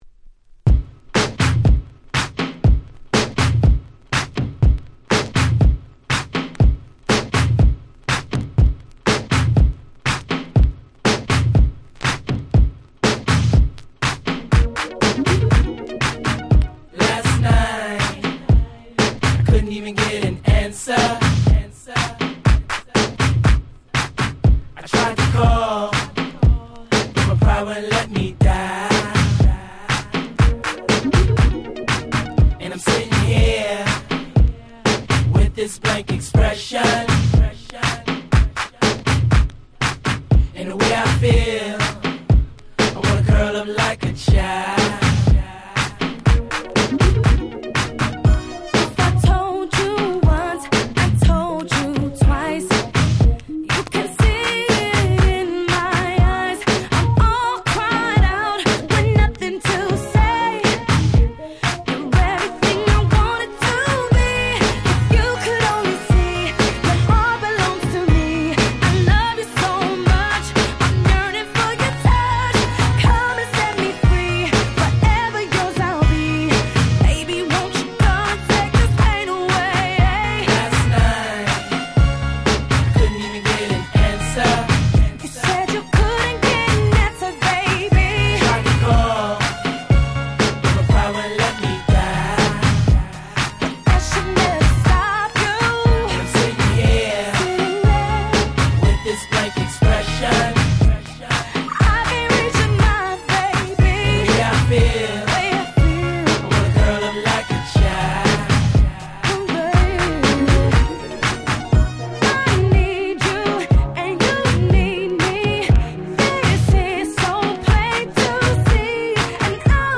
【Media】Vinyl 12'' Single
自分が当時好んで使用していた曲を試聴ファイルとして録音しておきました。